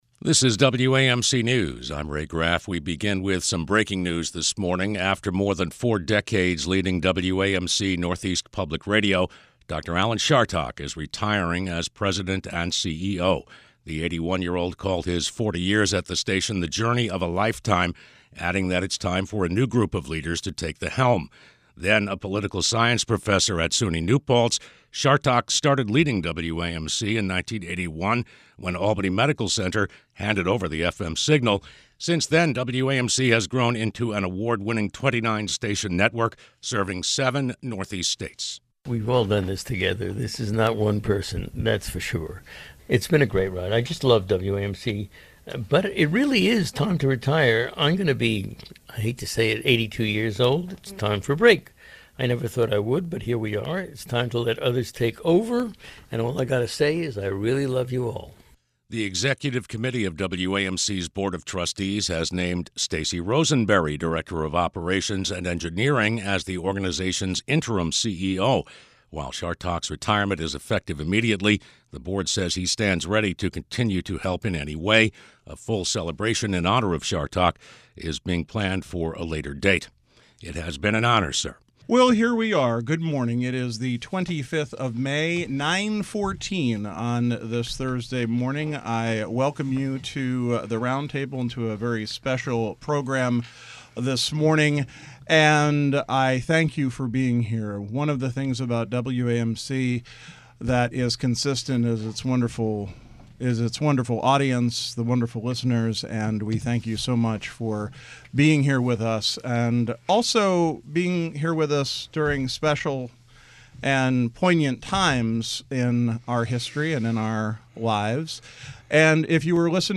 RT Panel